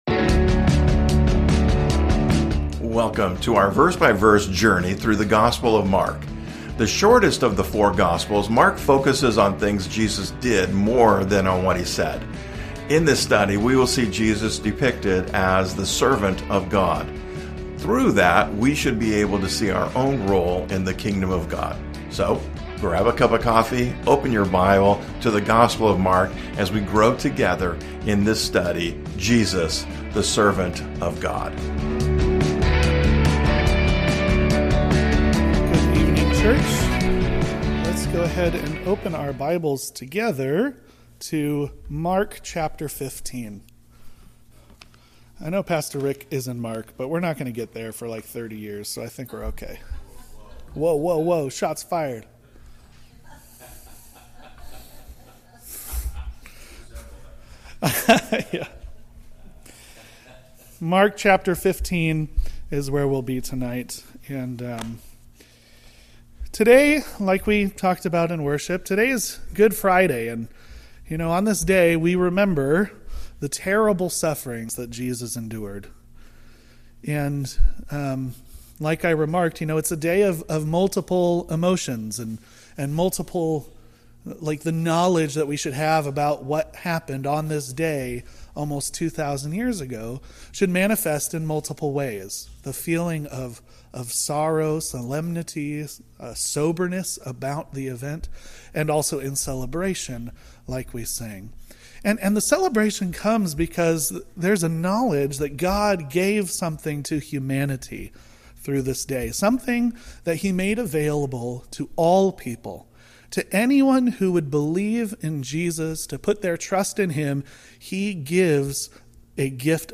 a message for Good Friday